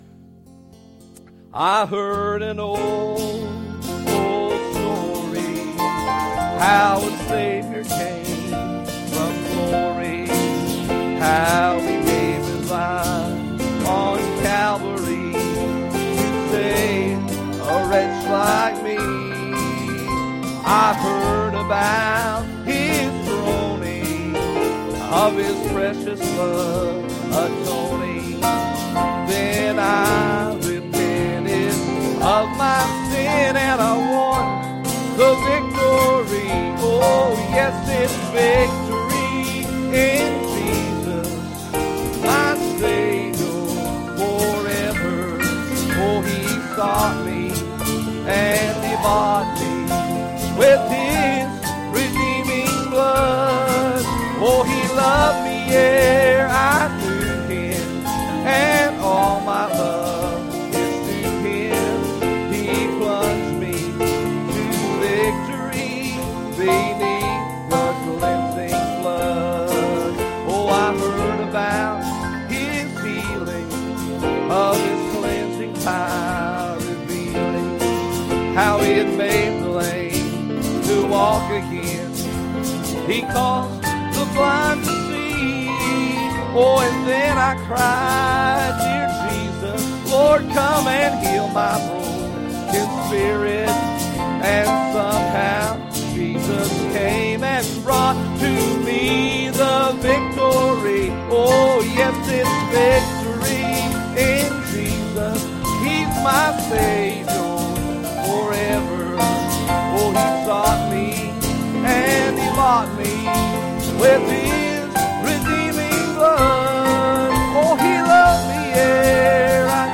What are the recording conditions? Passage: Isaiah 38:1 Service Type: Wednesday Evening